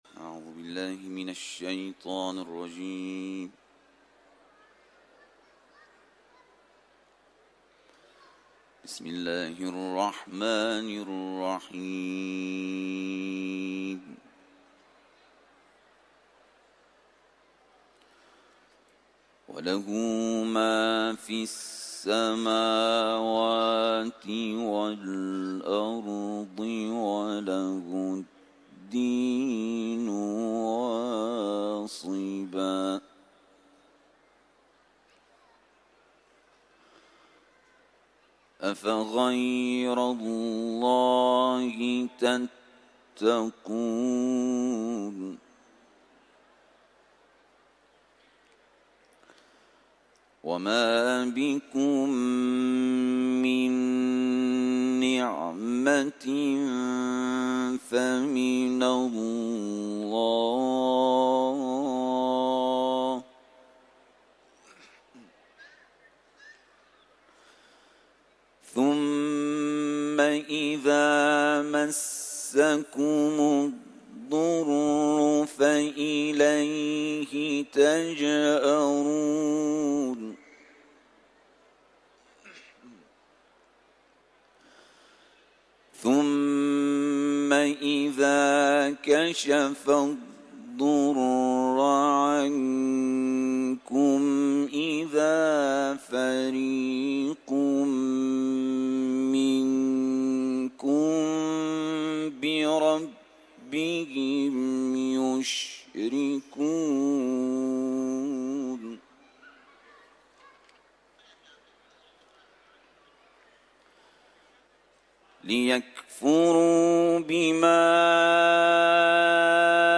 Etiketler: Kuran kârisi ، İranlı ، Kuran tilaveti ، Nahl suresi